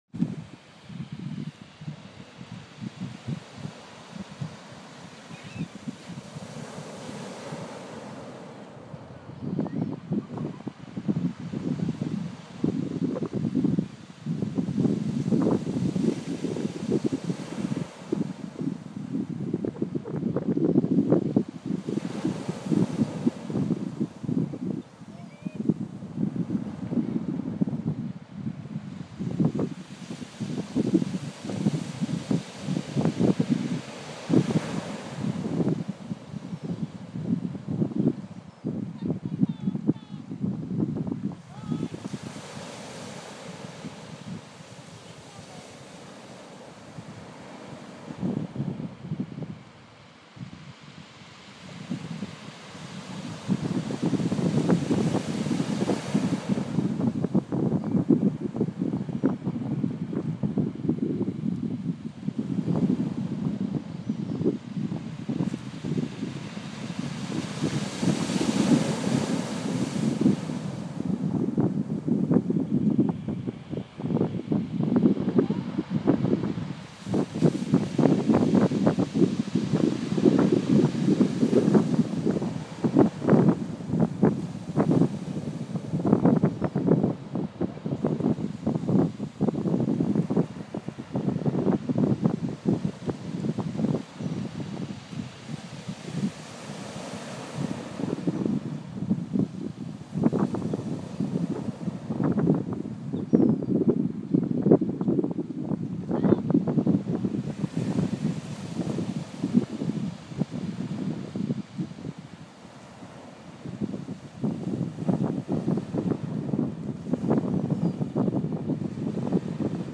Boxing Day at Budleigh Salterton
Field recording of waves on the beach